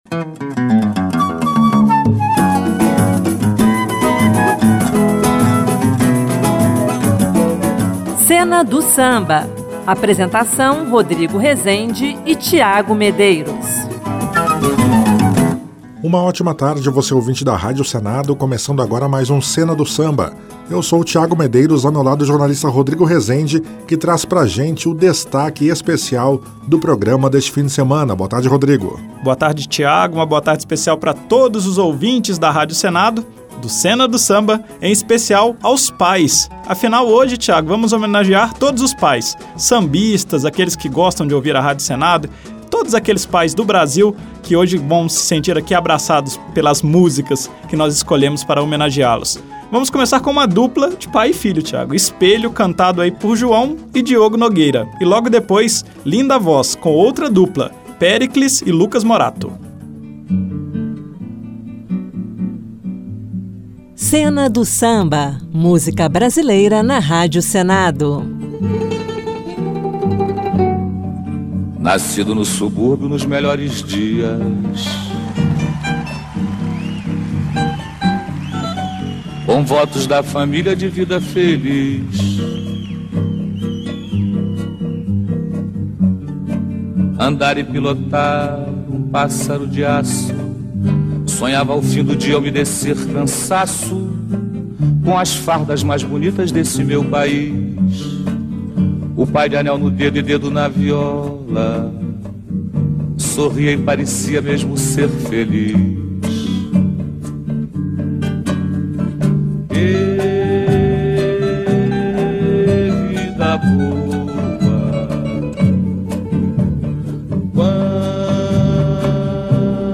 samba-enredo